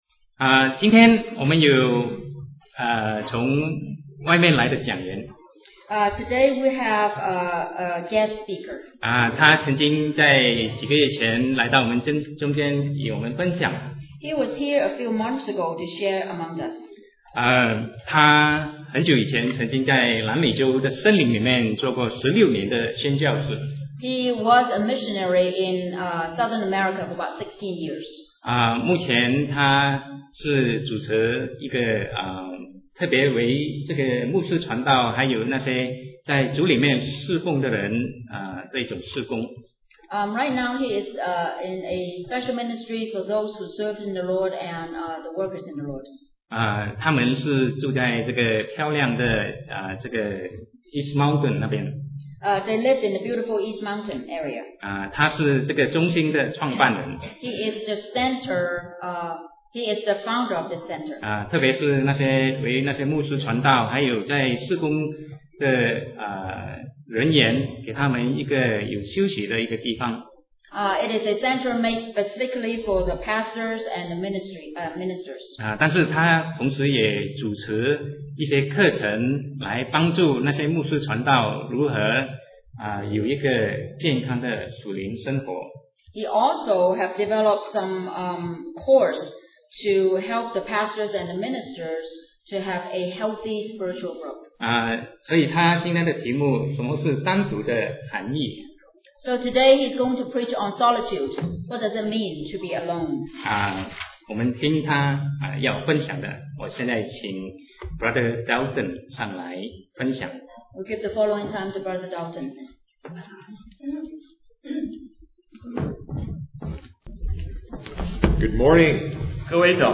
Sermon 2008-08-31 Solitude – What Does It Meant to Be Alone?